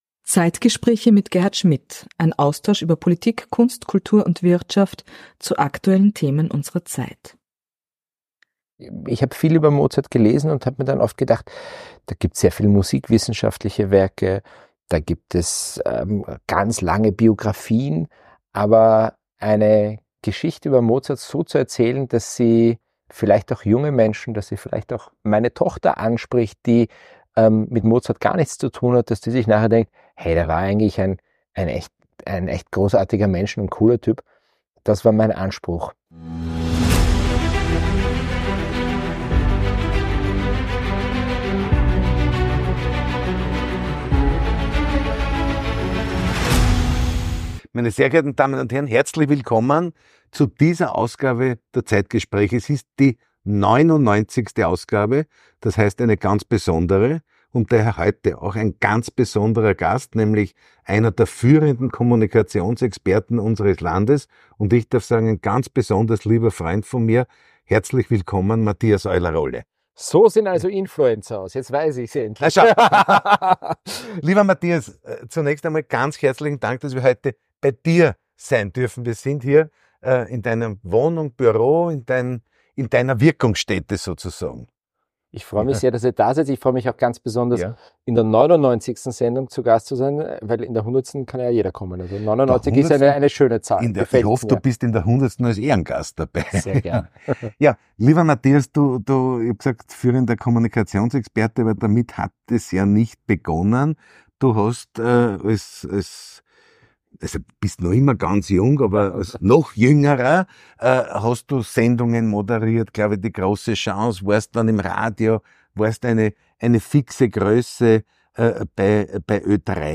Zeit für Gespräche – Zeit für Antworten.